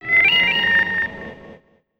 SPROING_1.wav